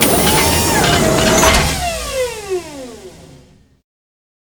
extend.ogg